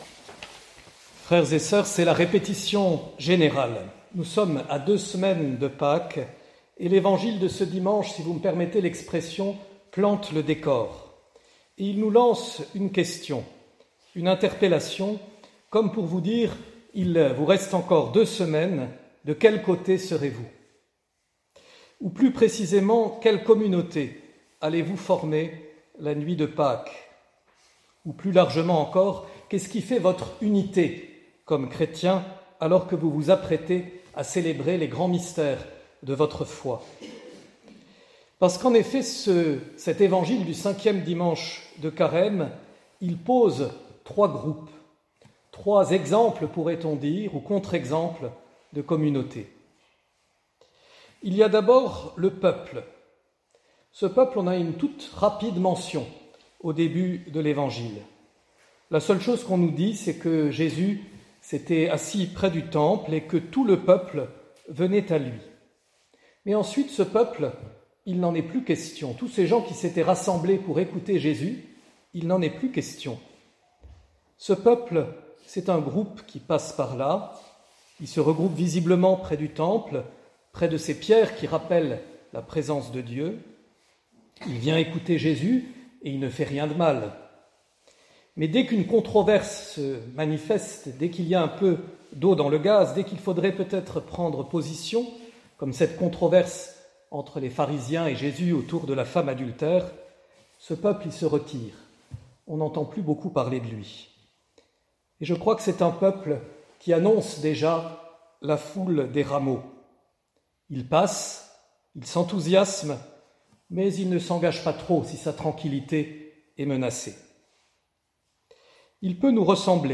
Pour ce cinquième dimanche de Carême